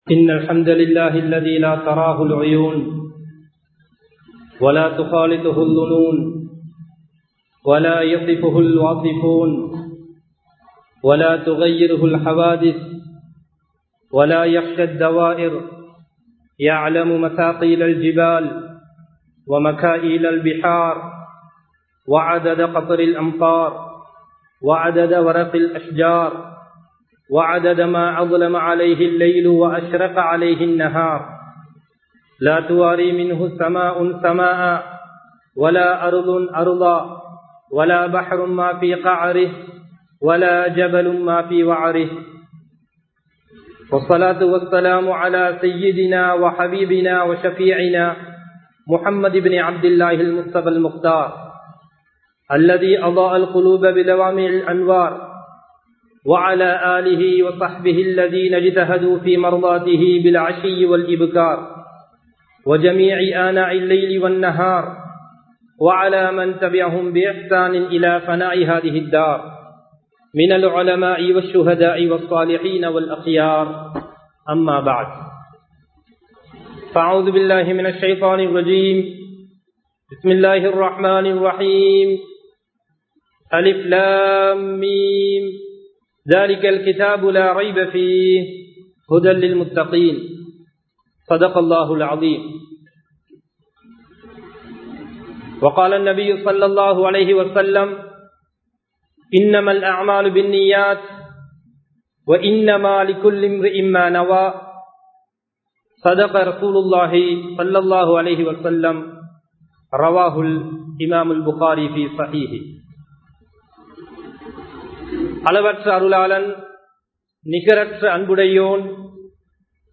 வார்த்தை தவறினால் (When Slipped the Tongue) | Audio Bayans | All Ceylon Muslim Youth Community | Addalaichenai